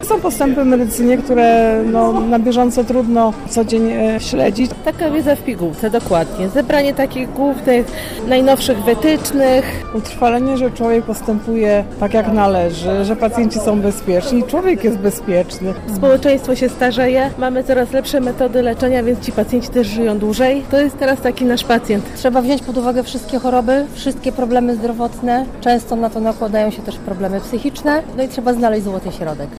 Lekarze, którzy dotarli na kongres pierwszego dnia, mówią, że wyjątkowo w piątek udało im się wyrwać z pracy w przychodni: